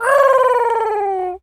pigeon_call_angry_15.wav